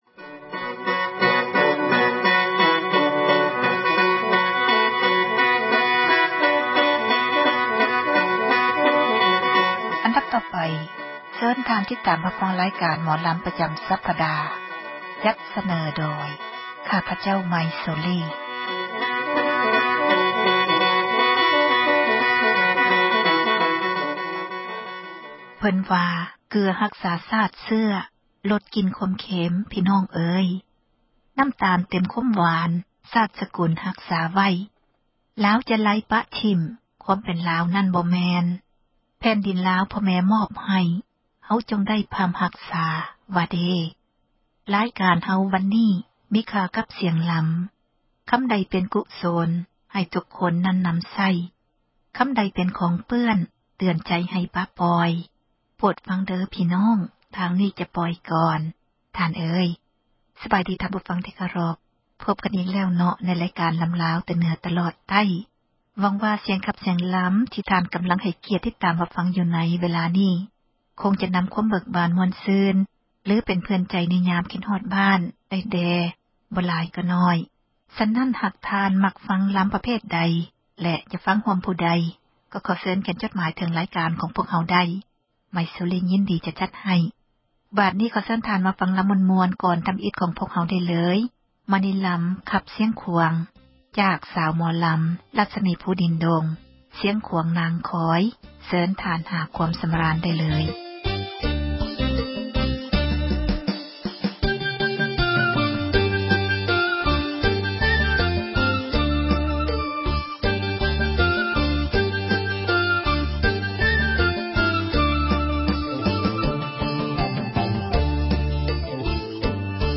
ຣາຍການ ໝໍລຳລາວ ປະຈຳ ສັປດາ ຈັດສເນີ ທ່ານ ໂດຍ